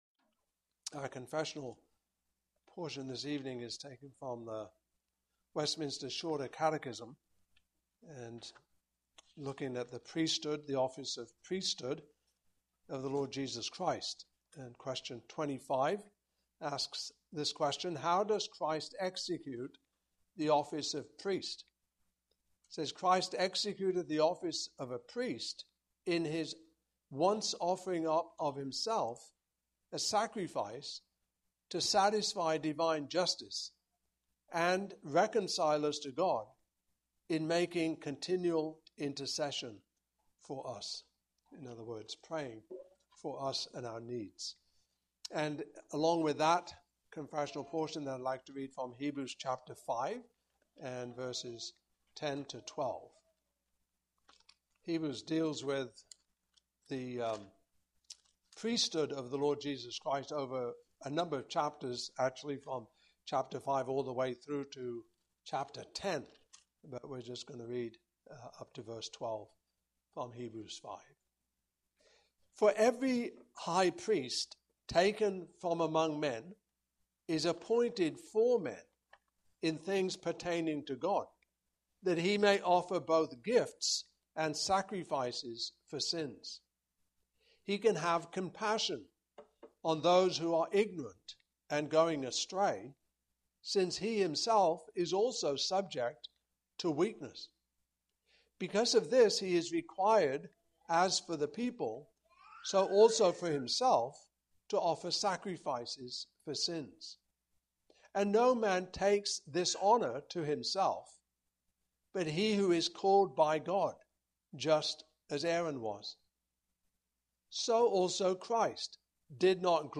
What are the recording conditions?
Westminster Shorter Catechism Passage: Hebrews 5:1-10 Service Type: Evening Service Topics